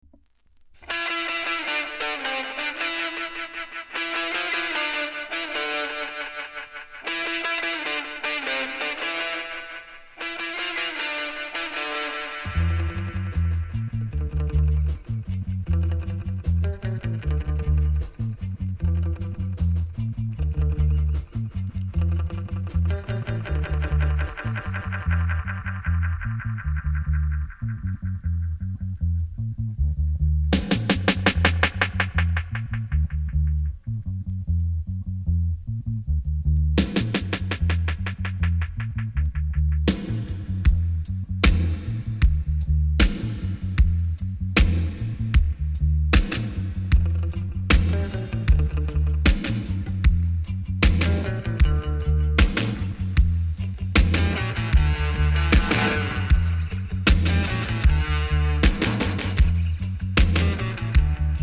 サイドB VERSION/DUB